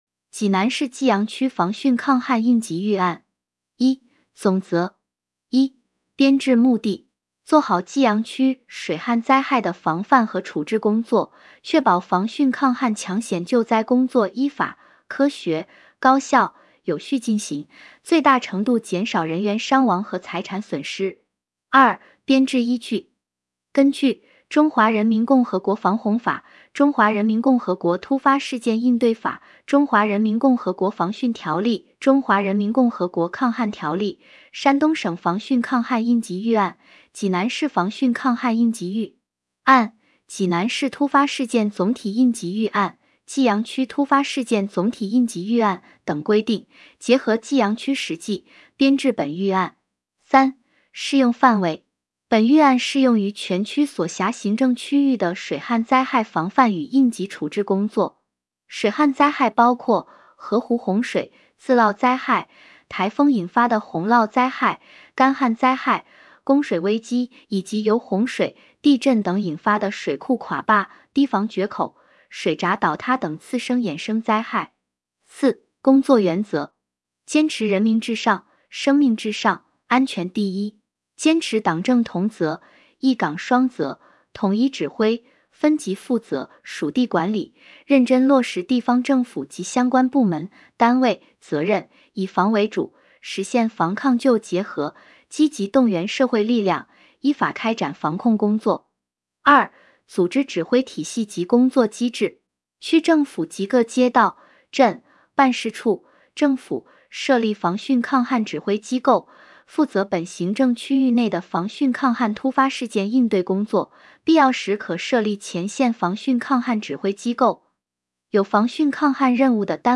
【有声朗读】《济阳区防汛抗旱应急预案》